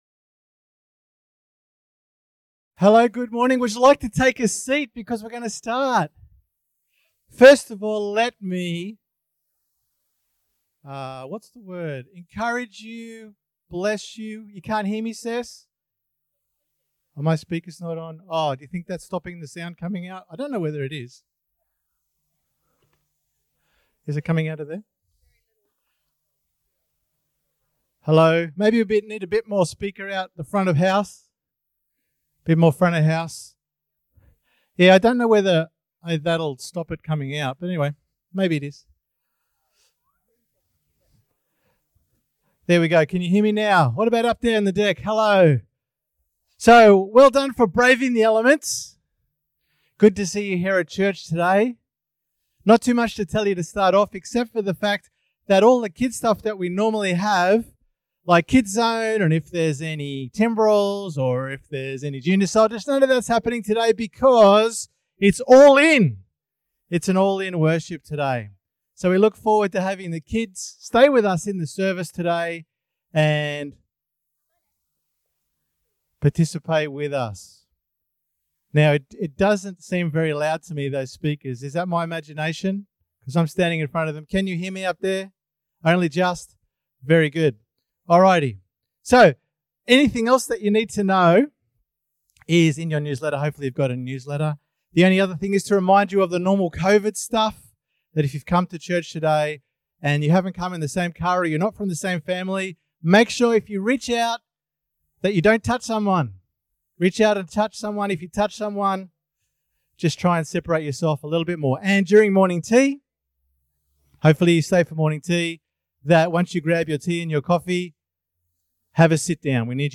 The morning meeting was All In, which included the children.